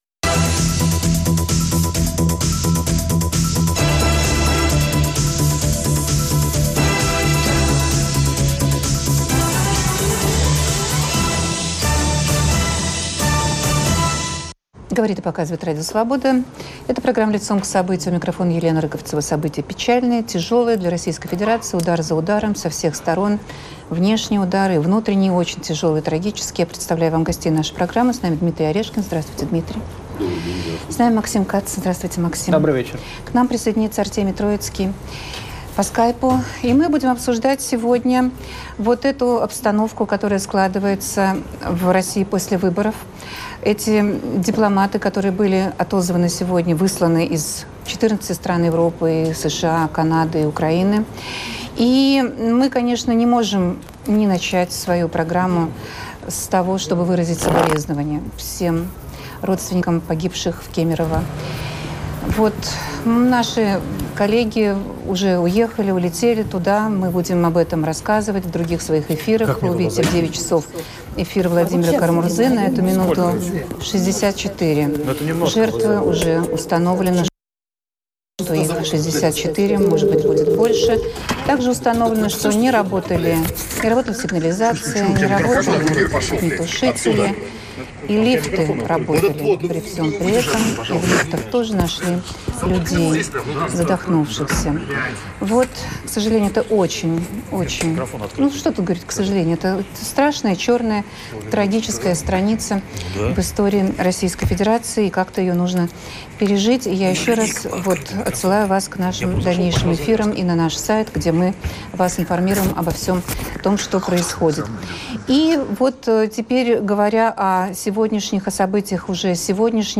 Как скажется обстановка "осажденной крепости" на судьбе российских противников режима Путина? Обсуждают аналитик Дмитрий Орешкин, политик Максим Кац и журналист Артемий Троицкий